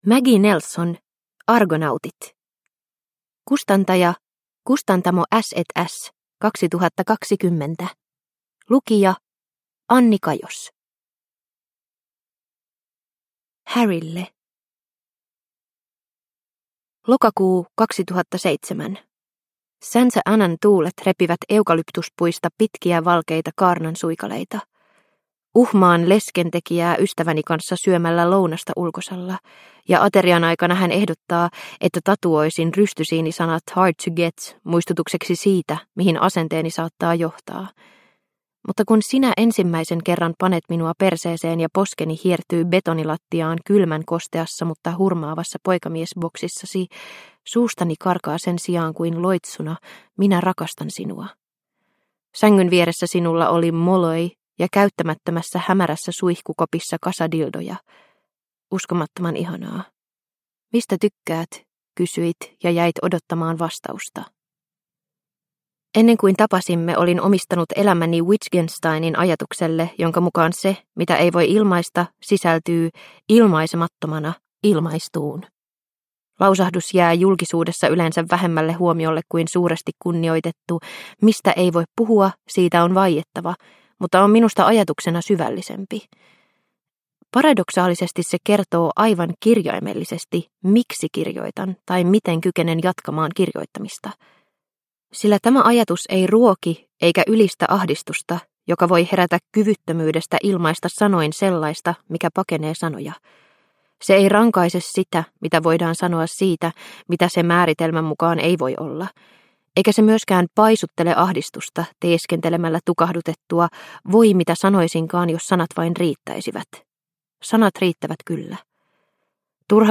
Argonautit – Ljudbok – Laddas ner